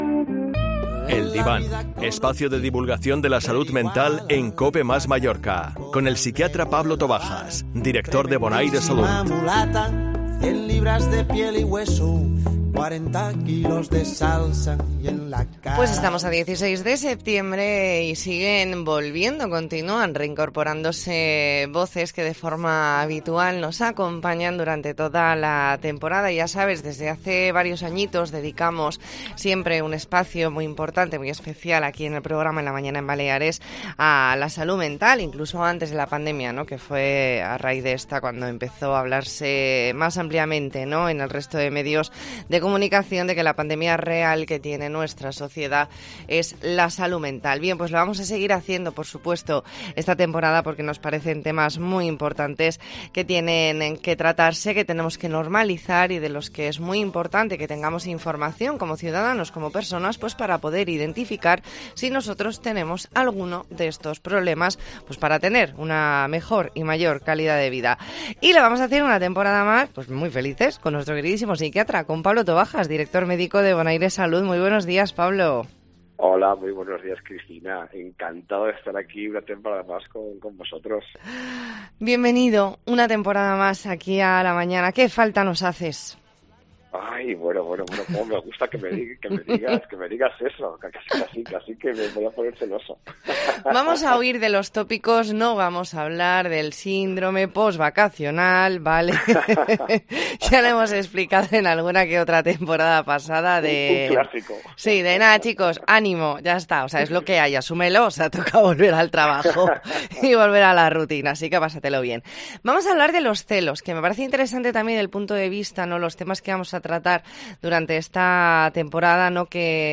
Entrevista en La Mañana en COPE Más Mallorca, viernes 16 de septiembre de 2022.